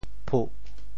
噗 部首拼音 部首 口 总笔划 15 部外笔划 12 普通话 pū 潮州发音 潮州 puh4 白 中文解释 噗〈象〉 倒地响声 [thump;flop] 只听得噗的一声,咕咚倒了一个。